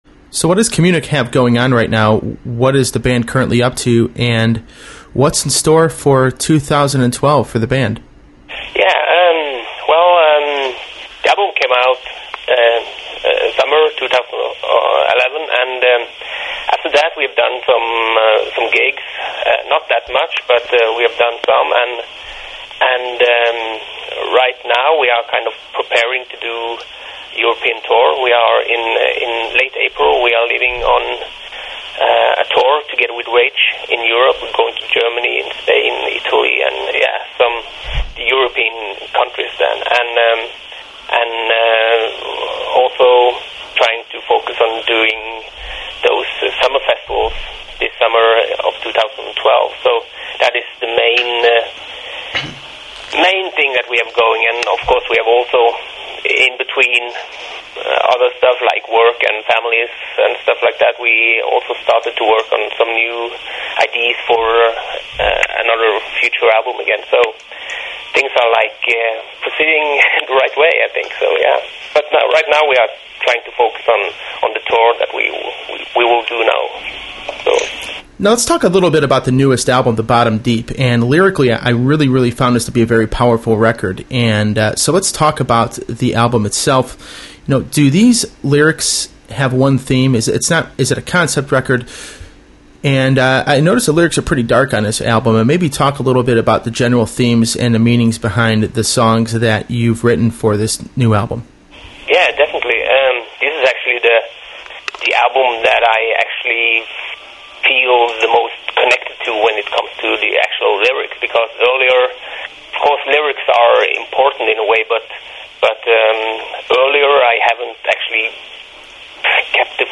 He was a fun person to talk to and I hope you all enjoyed the interview.